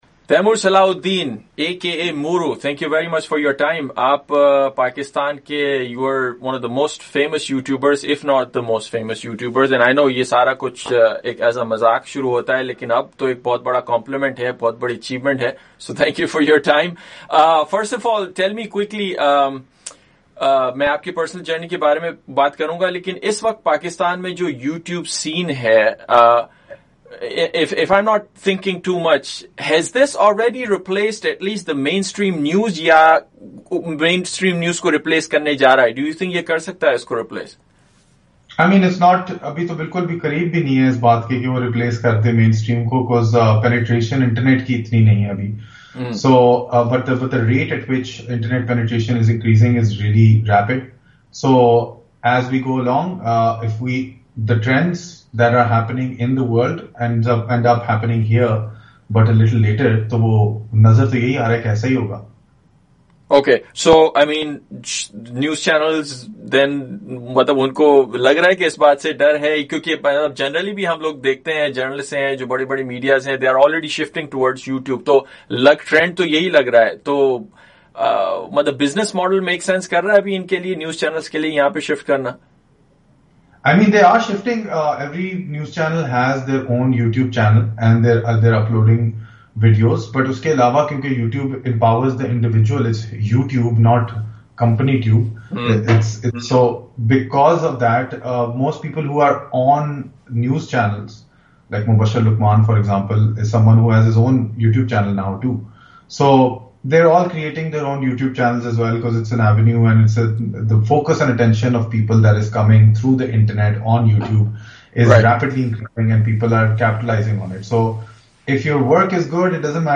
گفتگو۔